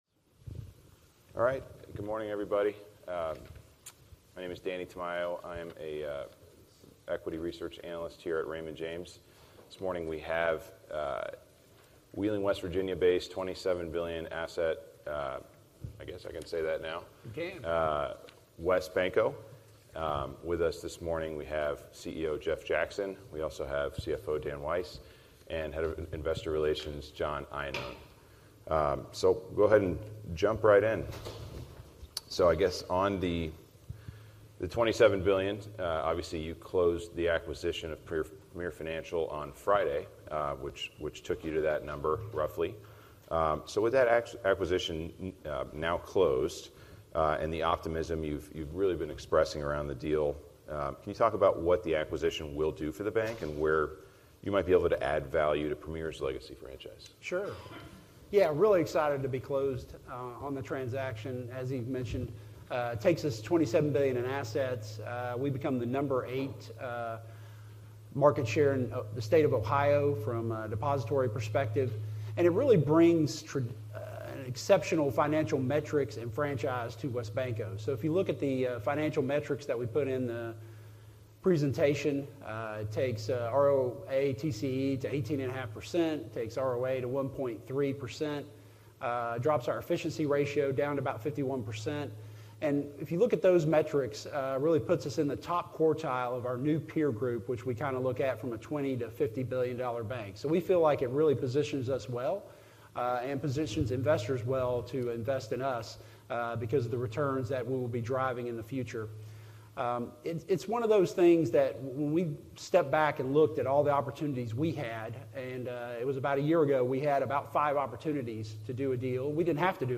WesBanco, Inc. - WesBanco, Inc. Fireside Chat at the Raymond James 46th Annual Institutional Investors Conference